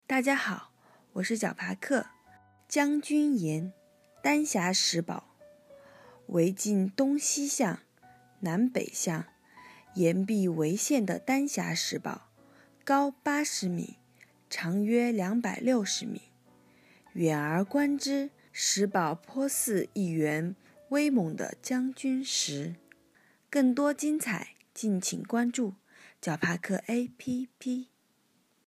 展开更多 将军岩----- ,nvnv 解说词: 丹霞石堡，为近东西向、南北向崖壁围限的丹霞石堡，高80m，长约260m，远而观之，石堡颇似一员威猛的将军石。